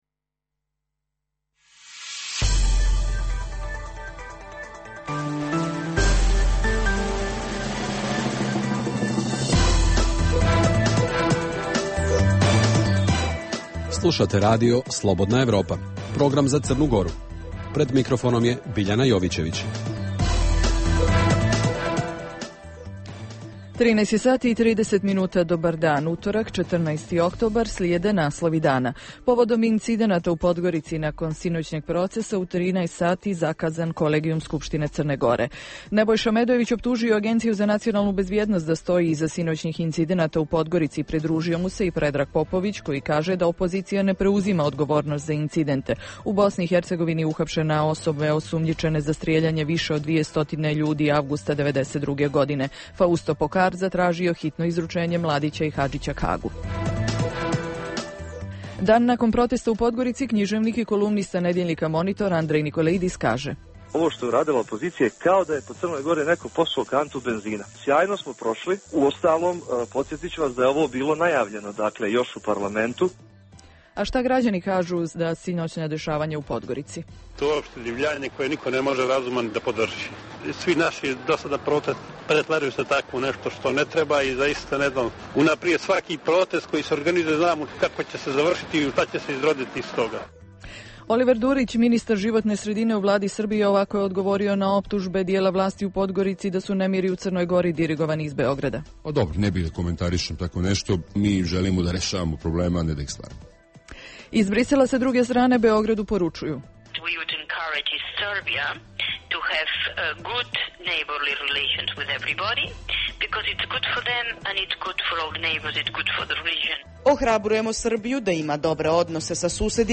Emisija je gotovo u cijelosti posvećena sinoćnjim protestima u Podgorici protiv odluke Vlade Crne Gore da prizna nezavisnost Kosova koji se okončao incidentima. O uzrocima i posljedicama govore političari, analitičari ali i građani iz Crne Gore i Srbije